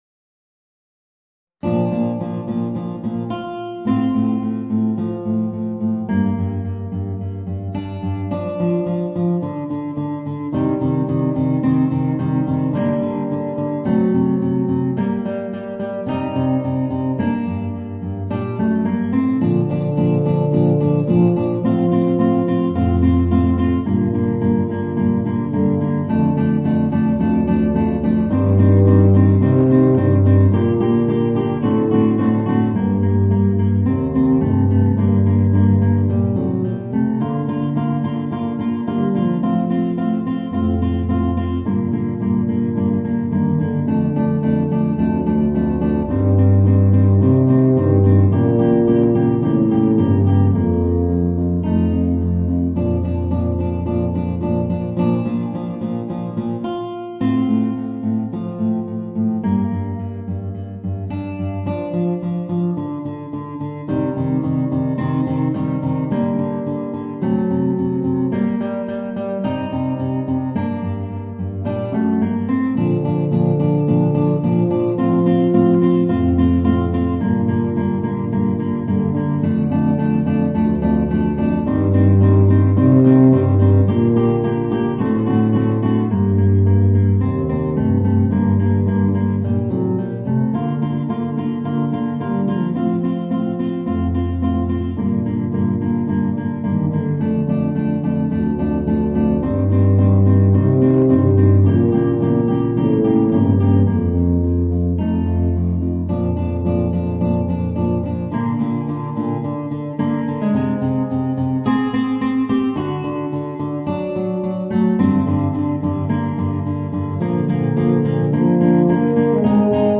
Voicing: Eb Bass and Guitar